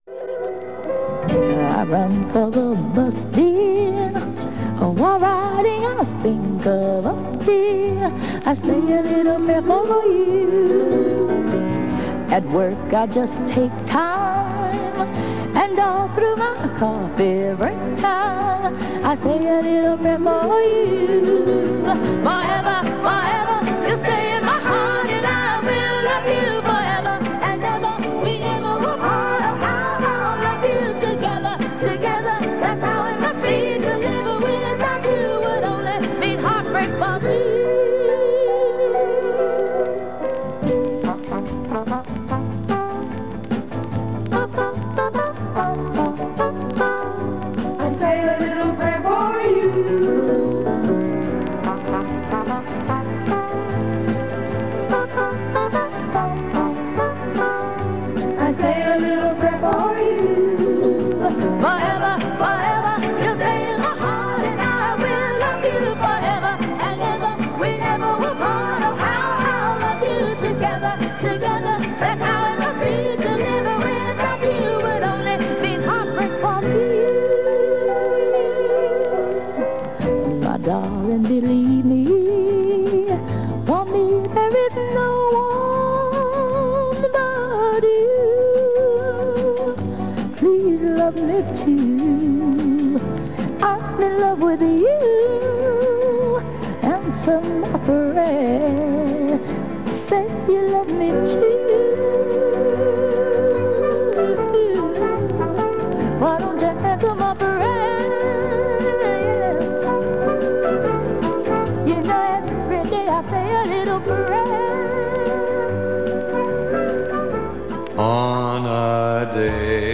Music is a mixture of easy-listening and classical and there are news headlines at the top of the hour.